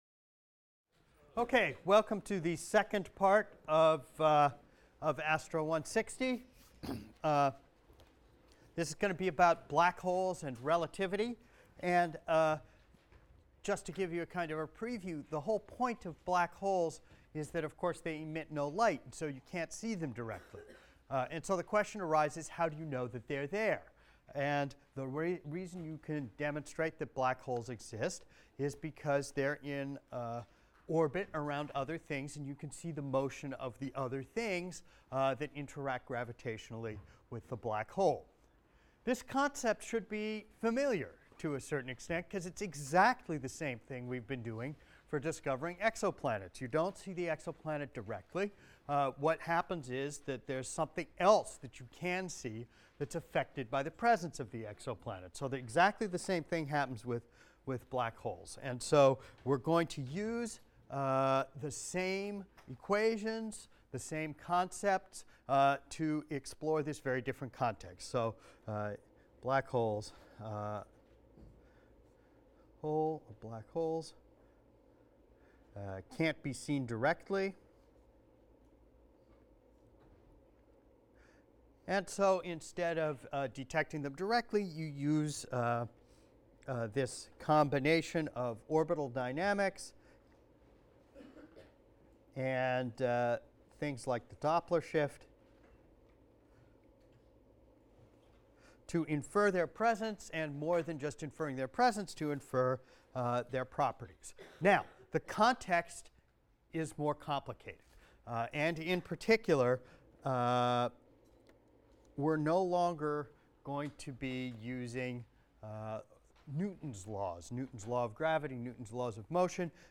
ASTR 160 - Lecture 8 - Introduction to Black Holes | Open Yale Courses